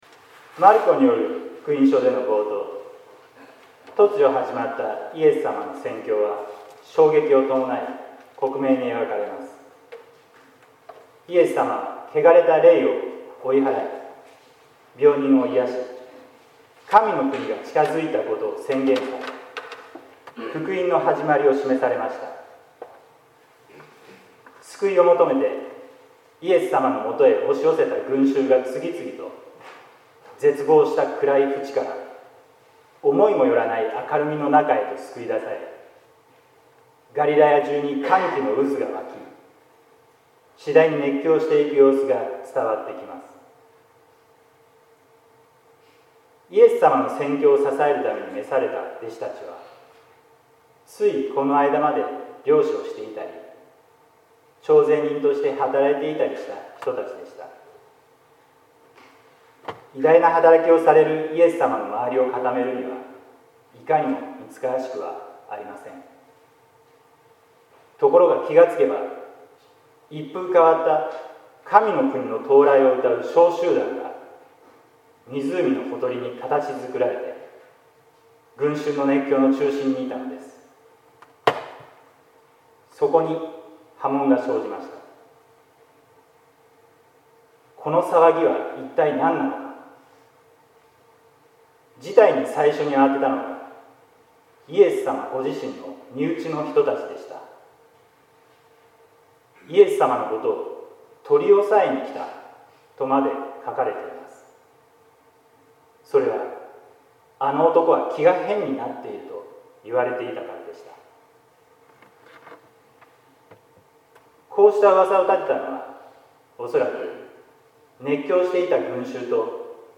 説教「あなたはどこにいるのか」（音声版）